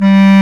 Index of /90_sSampleCDs/Roland LCDP04 Orchestral Winds/CMB_Wind Sects 1/CMB_Wind Sect 4
WND BSCLARG3.wav